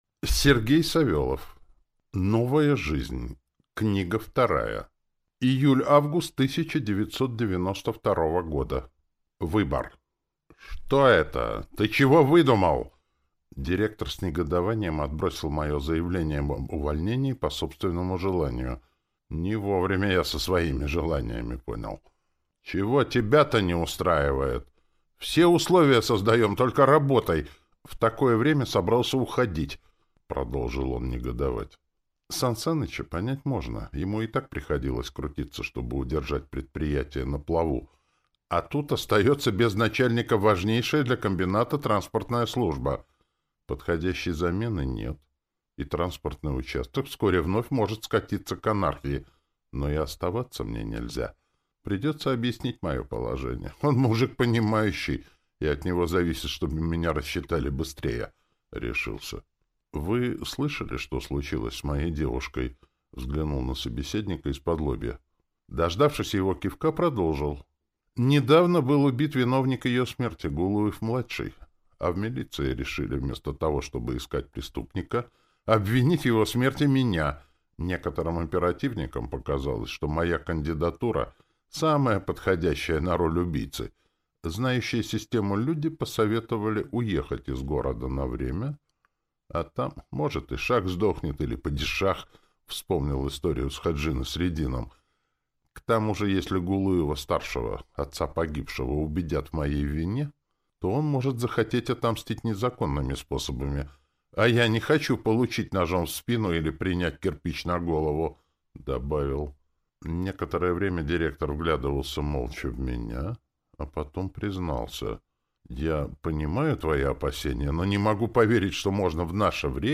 Аудиокнига Новая жизнь. Книга 2 | Библиотека аудиокниг